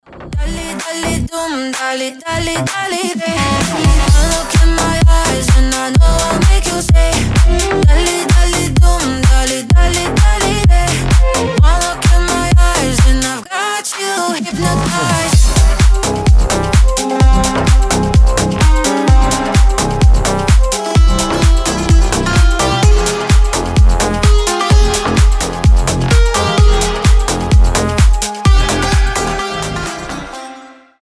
клубные , танцевальные
dance pop
slap house